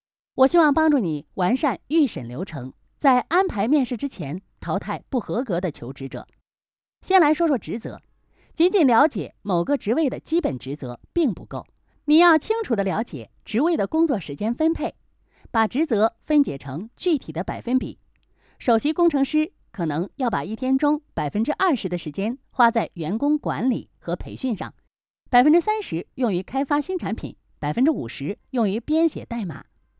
Chinese_Female_042VoiceArtist_5Hours_High_Quality_Voice_Dataset
Text-to-Speech